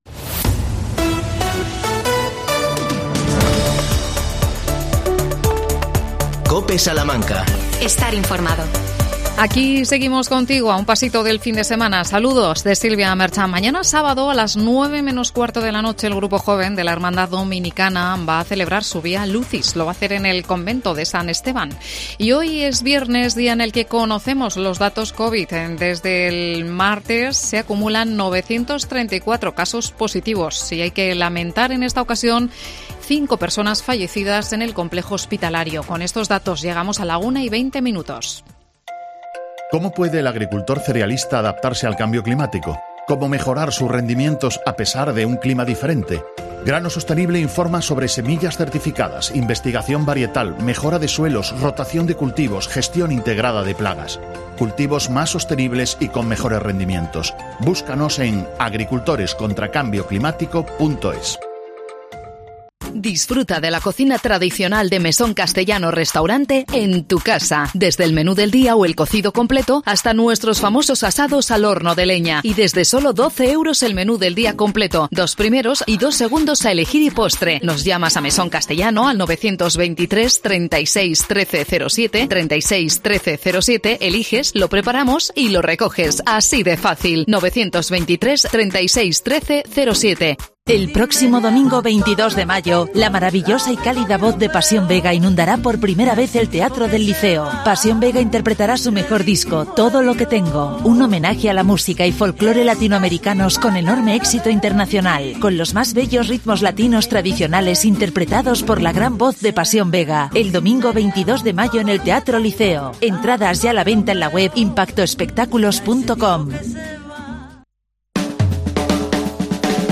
Entrevistamos